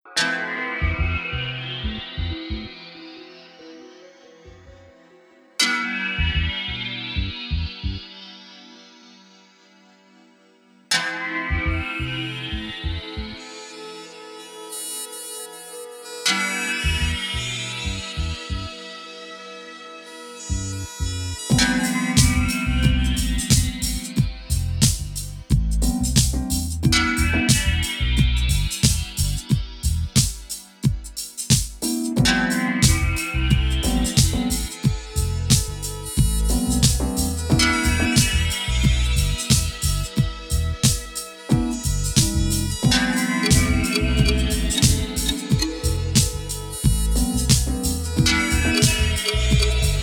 Idealna muzyka tła.